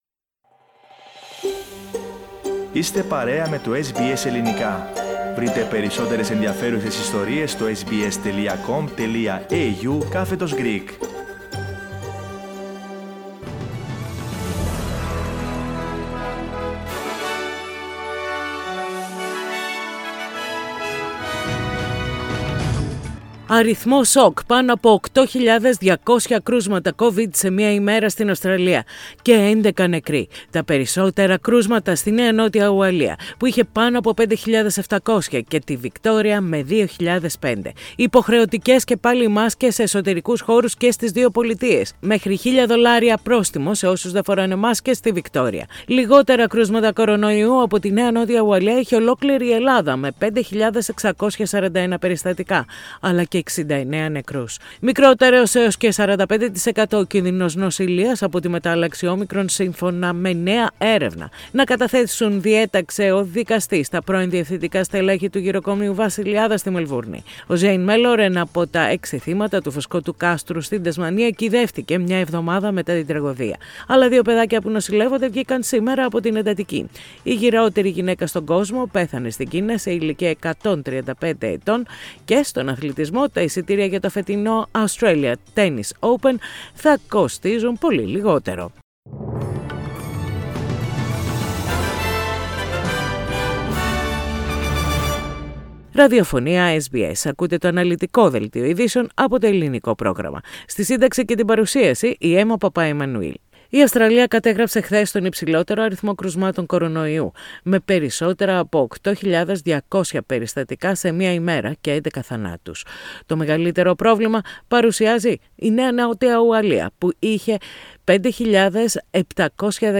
The detailed bulletin with the main news of the day from Australia, Greece, Cyprus and the international arena.